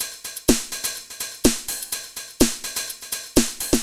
Index of /musicradar/retro-house-samples/Drum Loops
Beat 19 No Kick (125BPM).wav